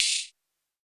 UHH_ElectroHatA_Hit-04.wav